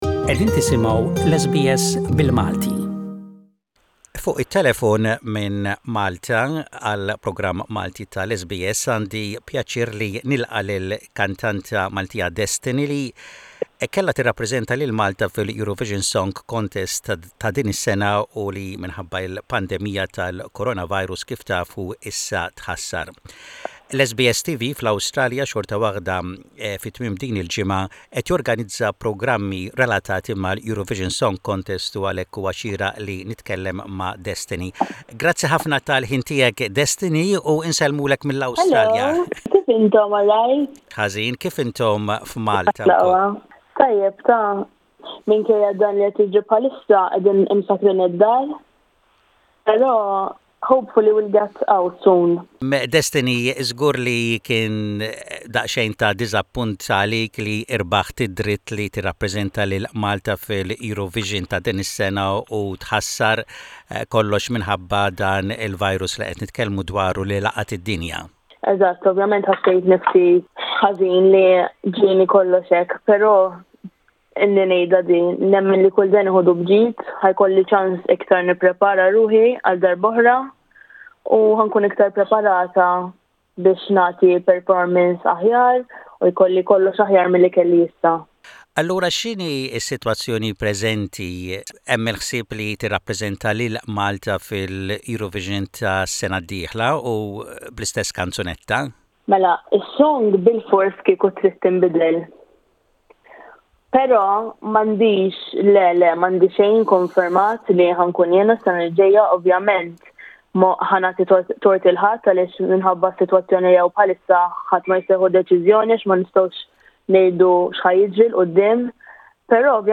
Interview with Maltese singer, Destiny who was selected to represent Malta this year.